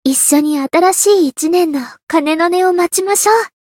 灵魂潮汐-安德莉亚-春节（摸头语音）.ogg